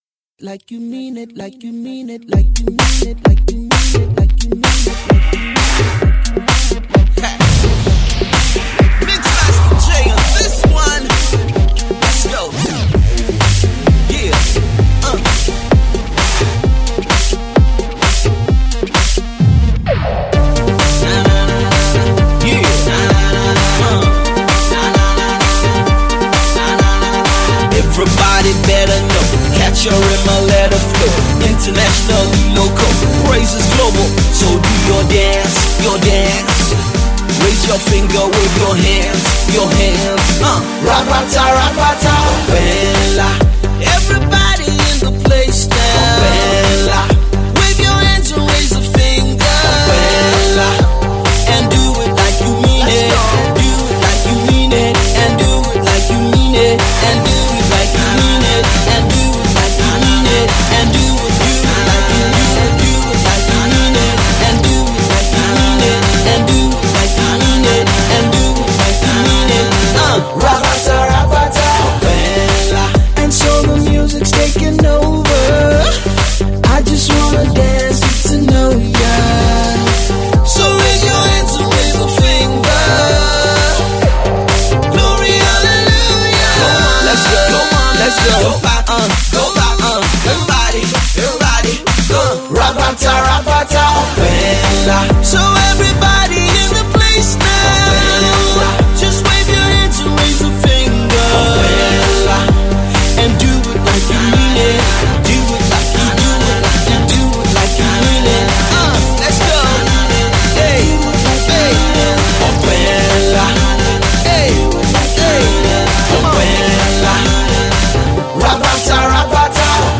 a playful, power party jam